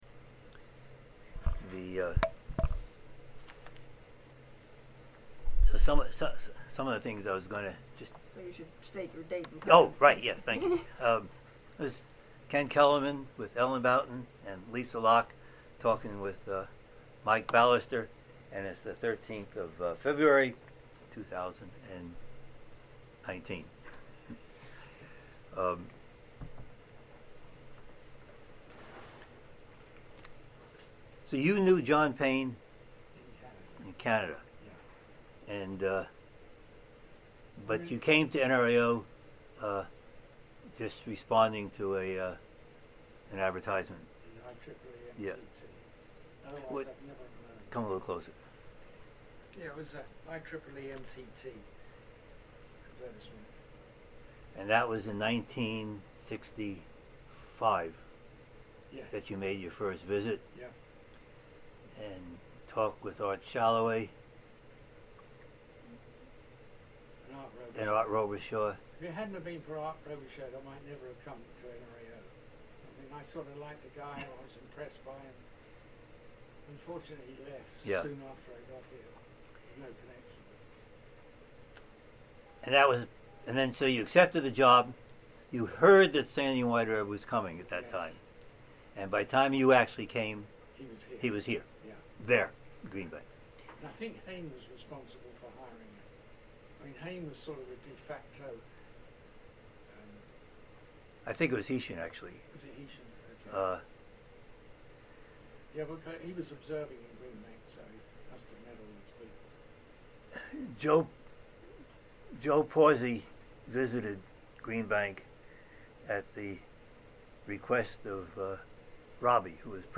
Type Oral History
Location Charlottesville, VA